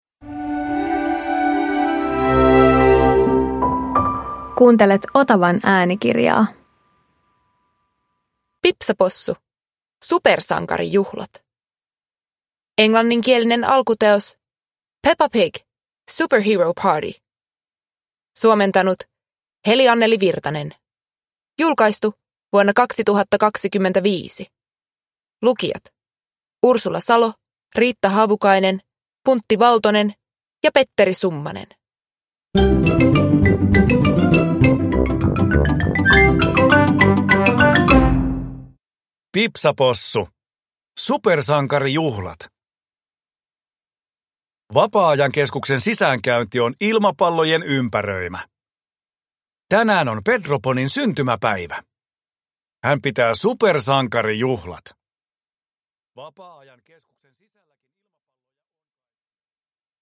Pipsa Possu - Supersankarijuhlat (ljudbok) av Various